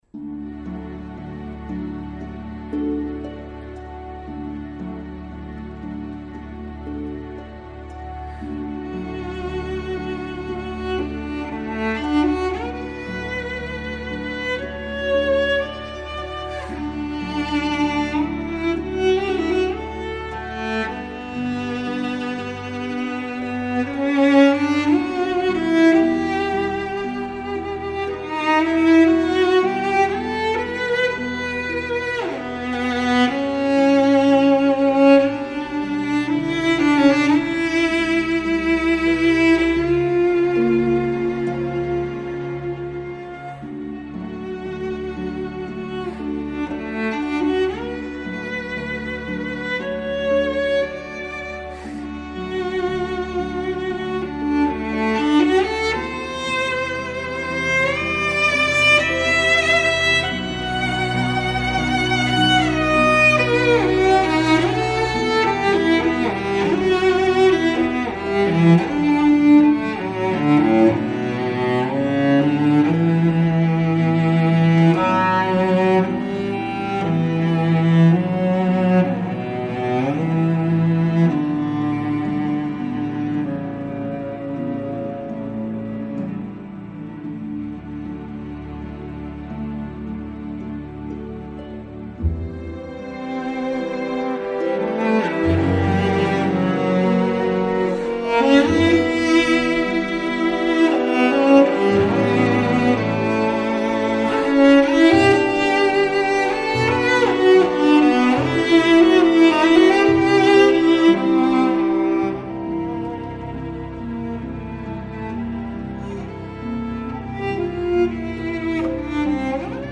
• Expertly played acoustic or electric cello to suit your event
Female Solo Cellist